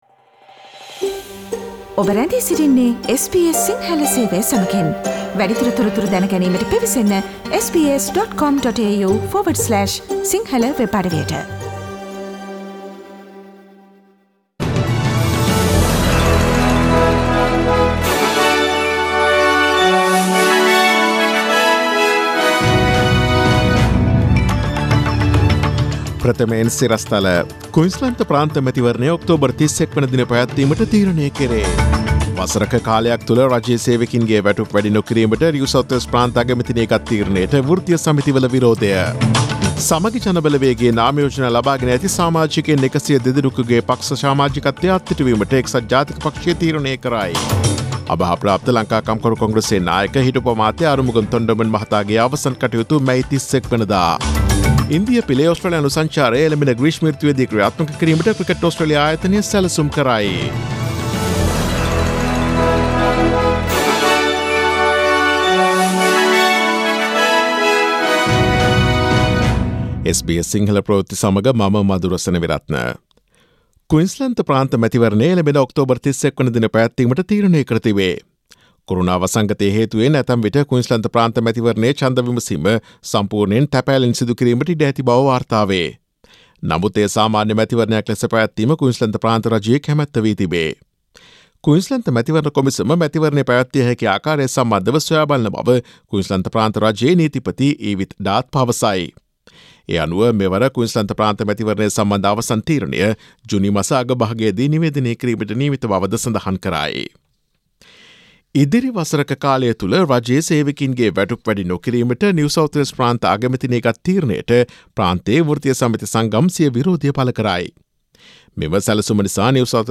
Daily News bulletin of SBS Sinhala Service: Thursday 28 May 2020